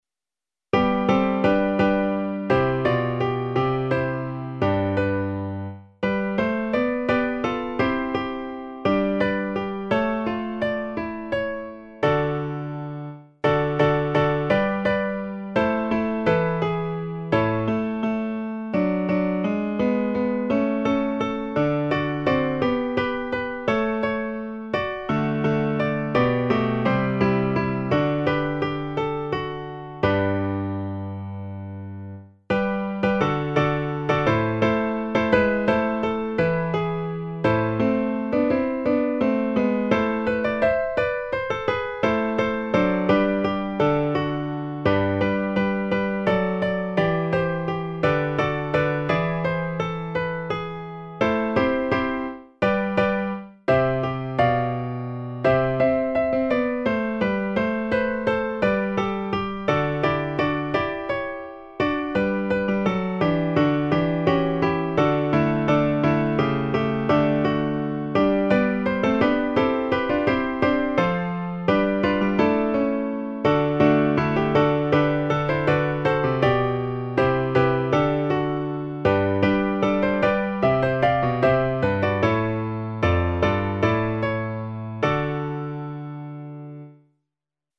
A 4 voces (Tiple I, II, Alto y Bajo)